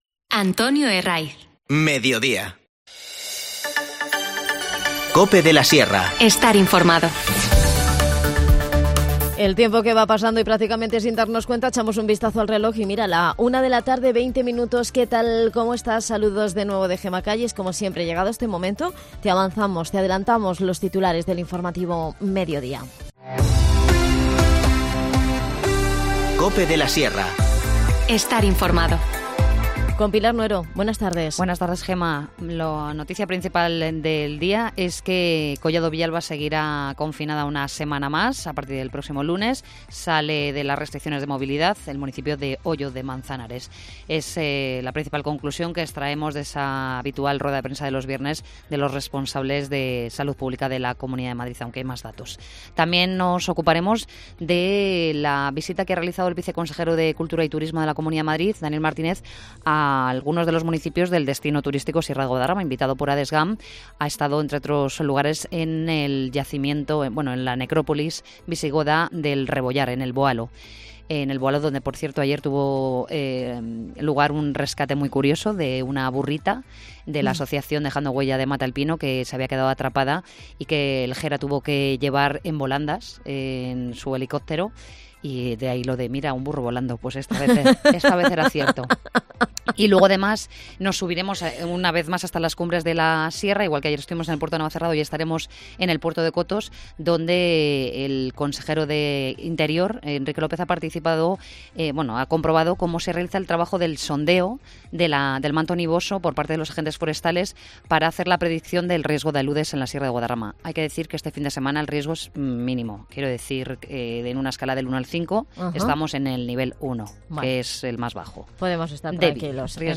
AUDIO: El cantante y periodista Juan Valderrama nos habla en el programa sobre su espectáculo "Mujeres de carne y verso" que presenta este viernes en la Casa...